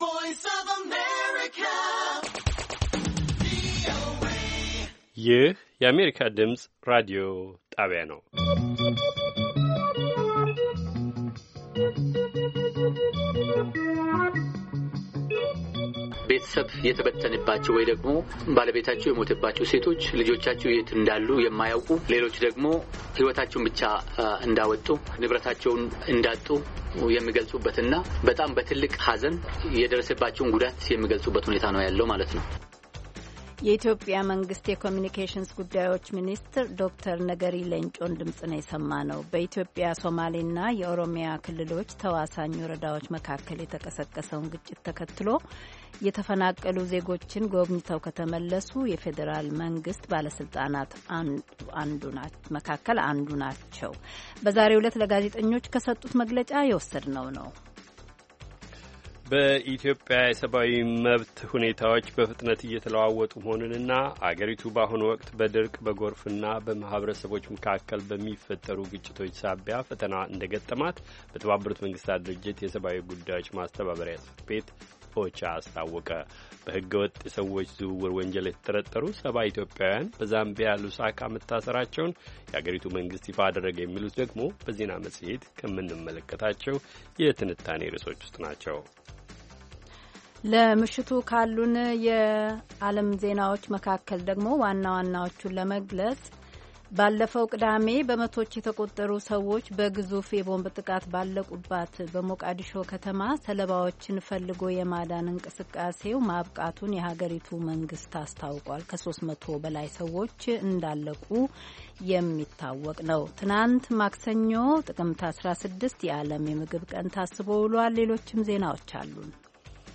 ማክሰኞ፡- ከምሽቱ ሦስት ሰዓት የአማርኛ ዜና